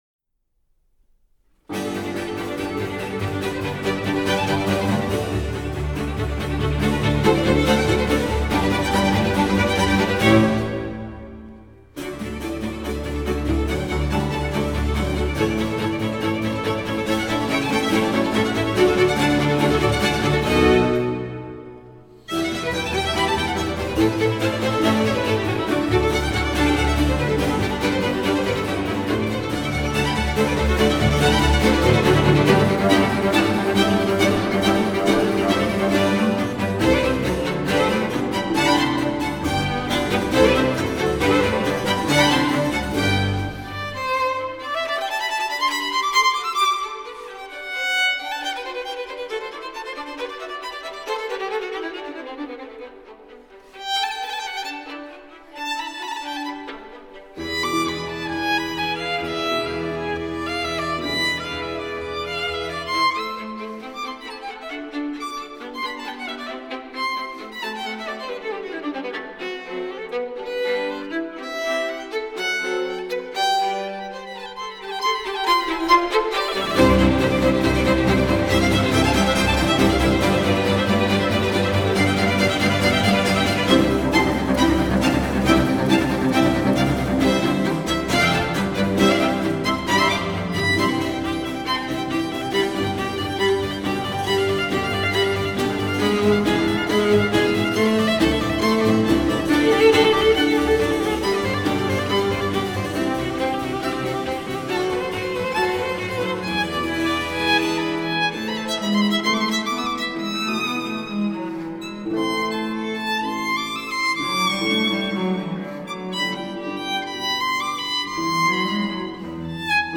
Eigteenth-century swing.
Allegro.